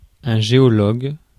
Ääntäminen
Synonyymit géologiste Ääntäminen France: IPA: [ʒe.ɔ.lɔg] Tuntematon aksentti: IPA: /ʒe.ɔ.lɔɡ/ Haettu sana löytyi näillä lähdekielillä: ranska Käännös 1. geólogo {m} Suku: m .